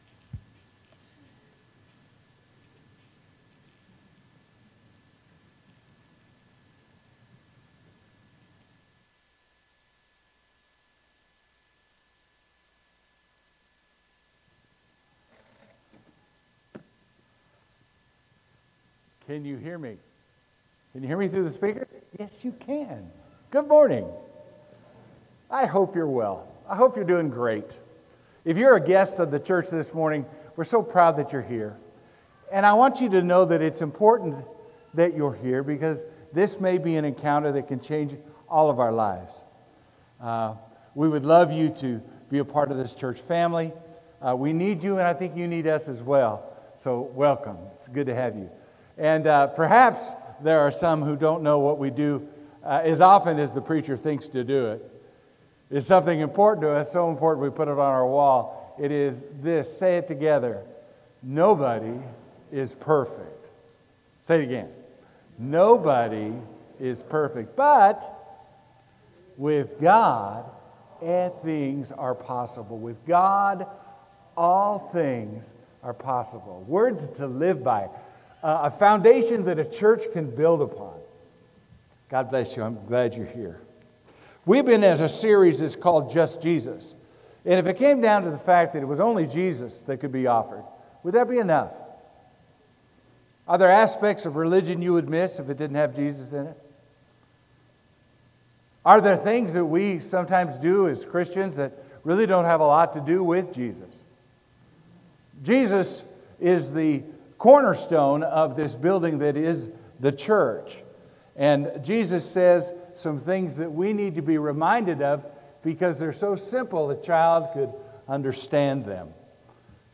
Sermon: Just Jesus (lesson 3)